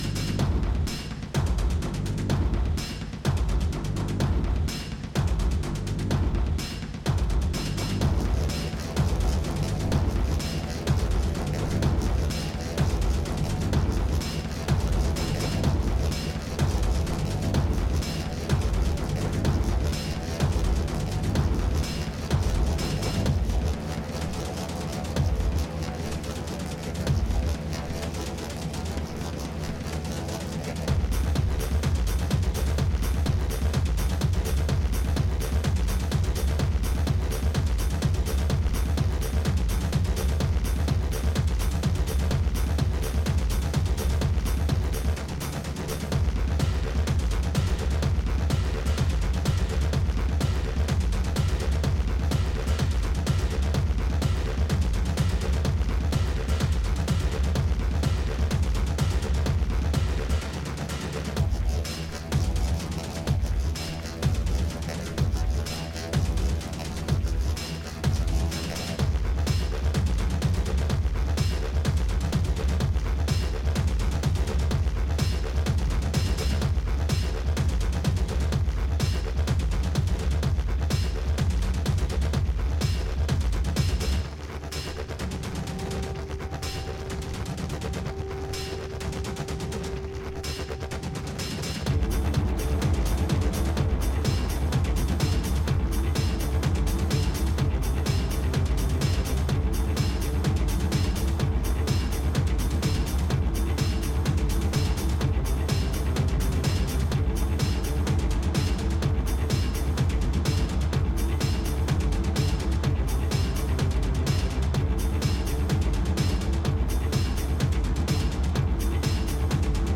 EBM/Industrial, Techno